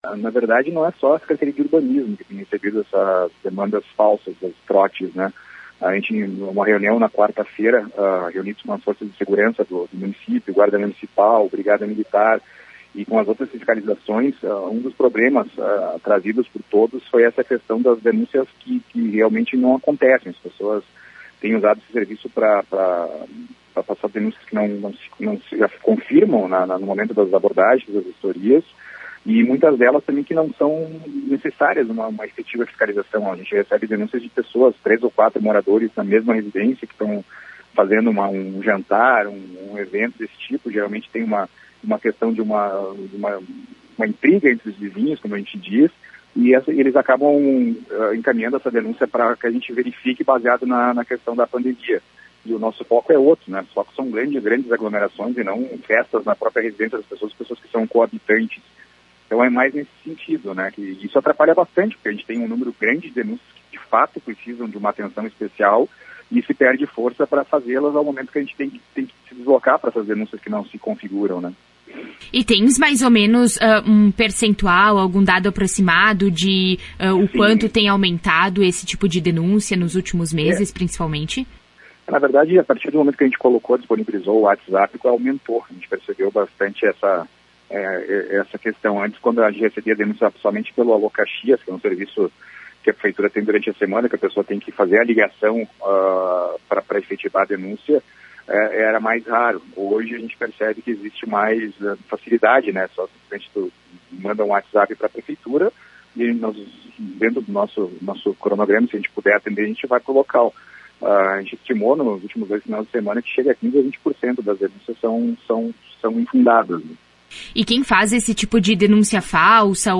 Em entrevista à Tua Rádio São Francisco ele explicou como a prática prejudica o trabalho das equipes.